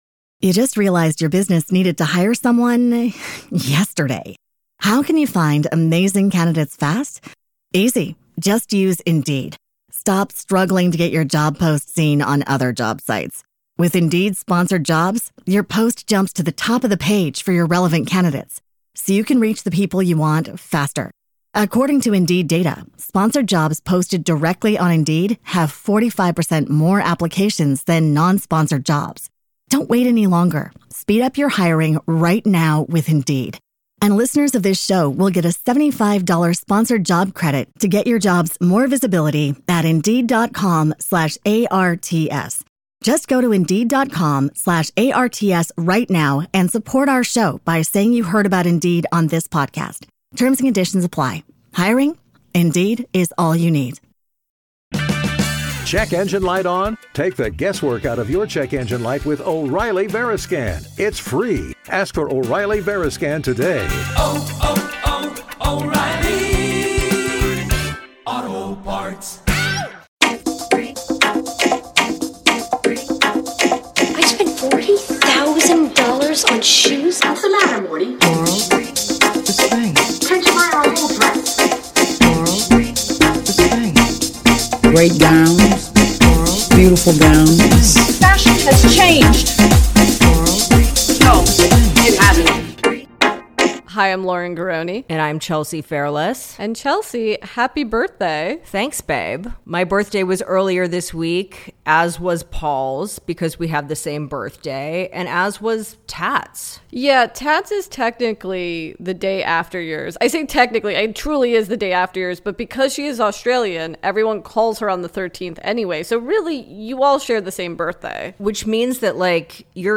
On today’s episode, we answer hotline calls and spend WAY too much time talking about the critically panned 1994 action comedy Exit to Eden.